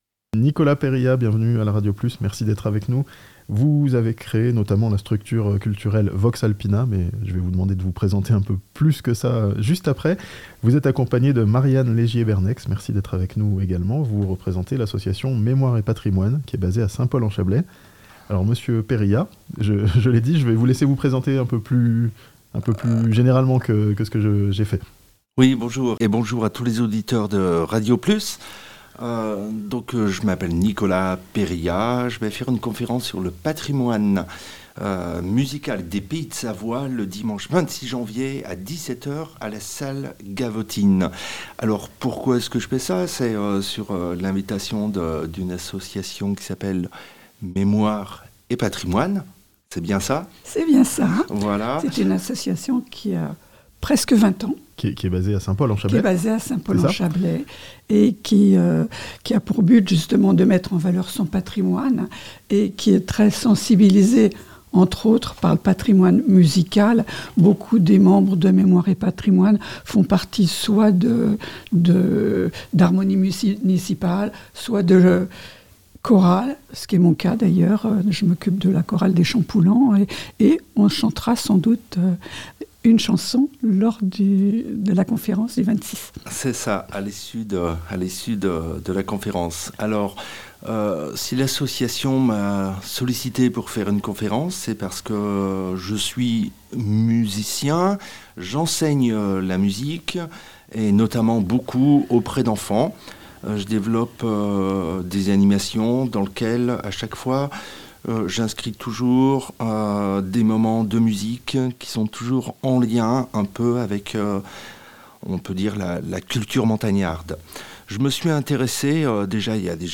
Une conférence sur le patrimoine musical des Pays de Savoie, dans le Chablais ce dimanche (interview)